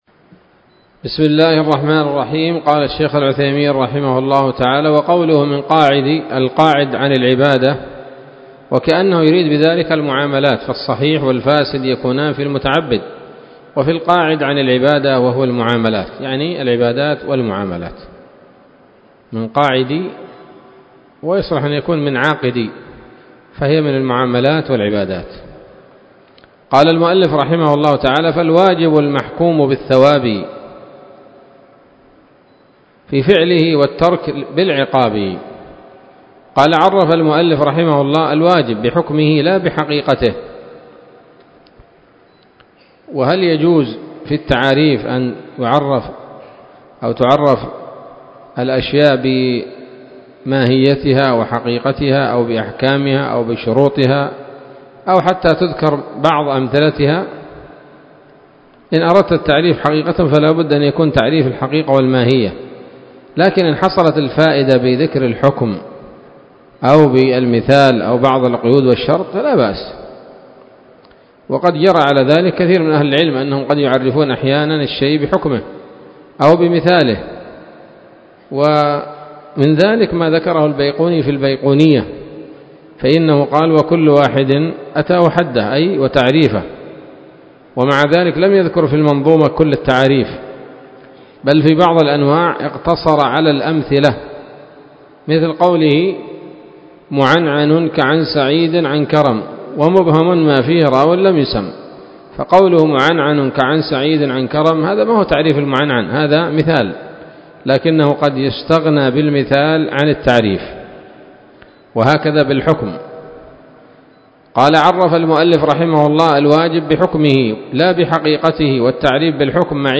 الدرس التاسع من شرح نظم الورقات للعلامة العثيمين رحمه الله تعالى